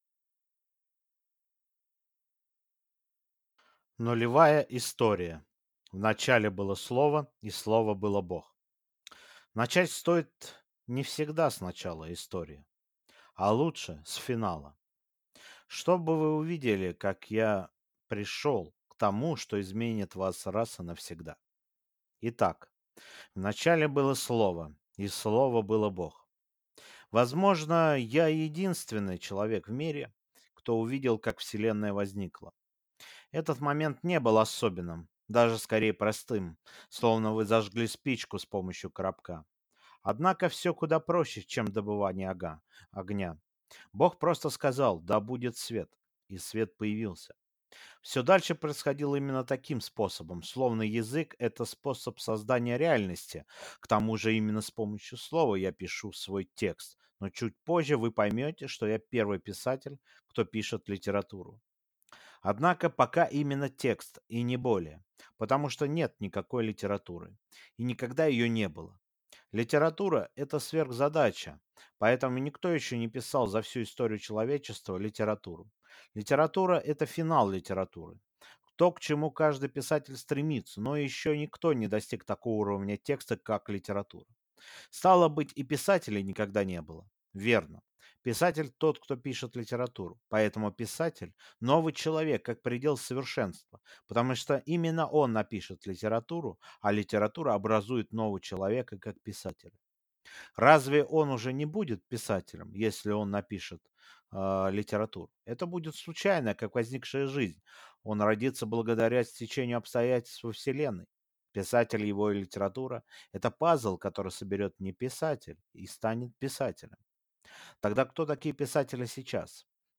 Аудиокнига Бункер 007 | Библиотека аудиокниг